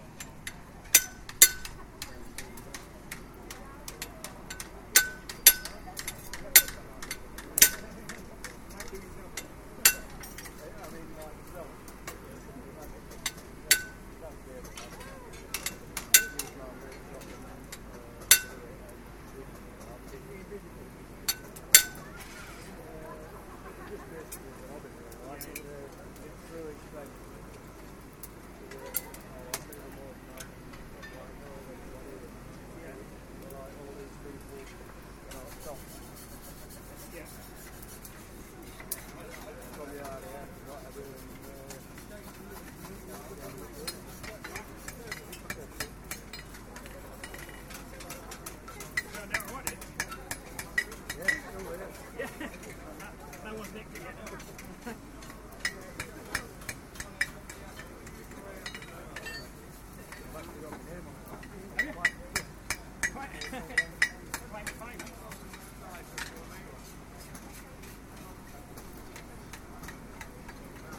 Blacksmithing demonstration at 'Galvanise Festival Forge-In', Abbeydale Industrial Hamlet